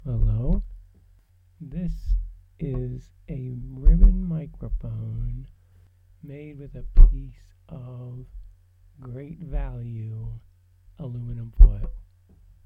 It was faint, it was muffled, but it was real sound!
Household foil mic